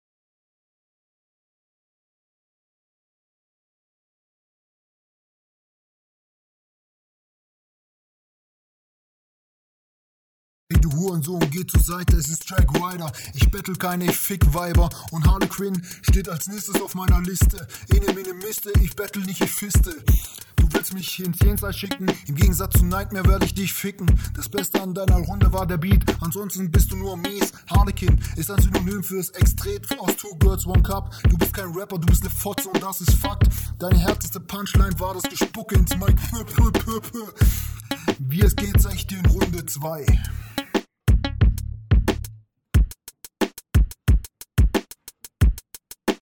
Ja moin erstmal 10 sekunden garnichts.